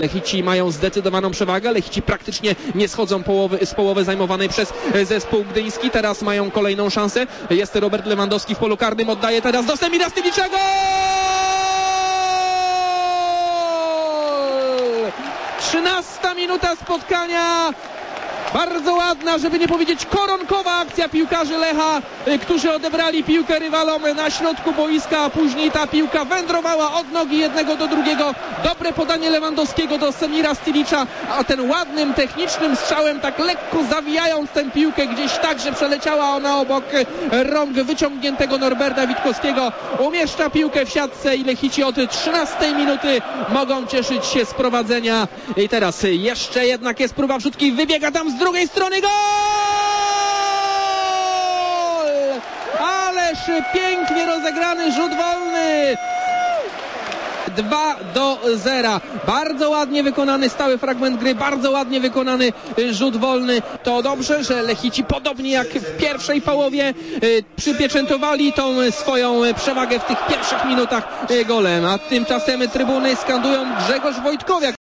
Posłuchajmy jak padały bramki w ostatnich meczach. Nie da się ukryć, że komentatorom Radia Merkury trudno było usiedzieć na stanowiskach.